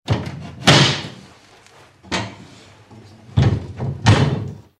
Cerrar las puertas de una alacena de cristal
Sonidos: Acciones humanas
Sonidos: Hogar